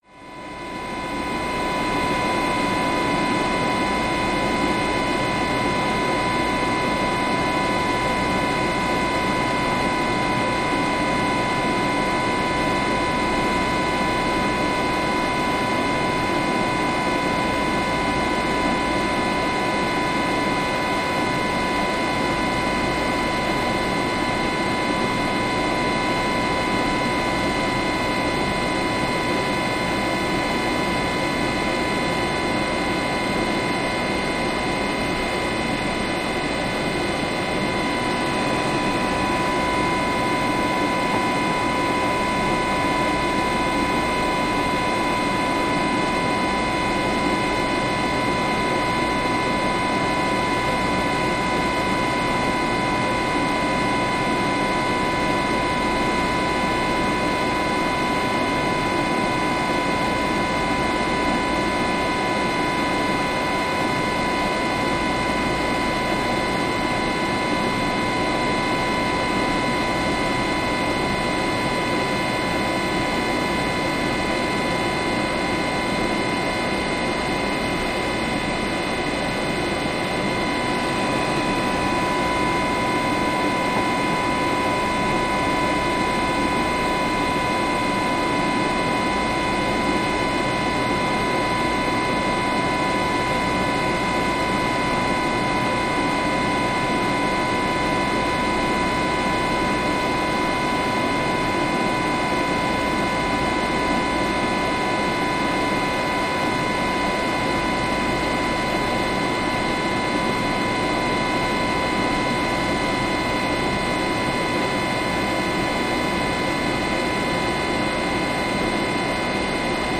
Industrial Fans Blowers Steady